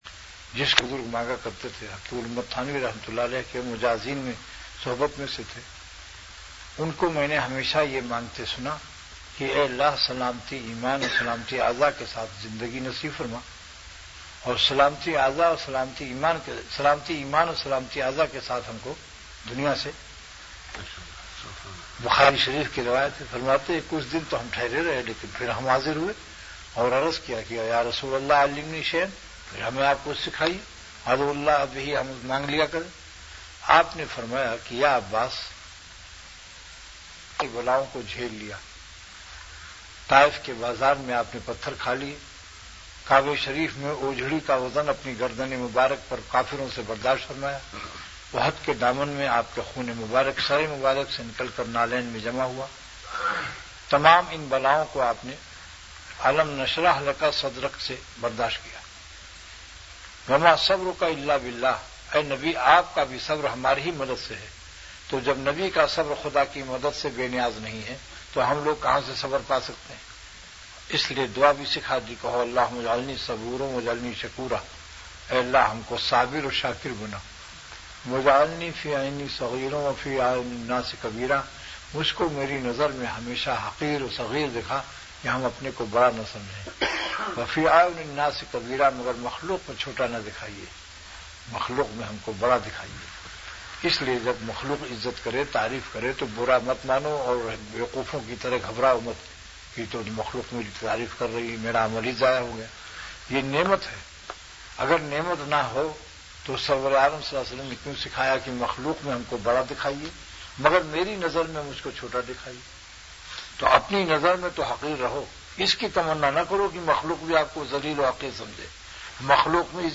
اور اِس ضمن میں بہت درد بھرا بیان فرمایا۔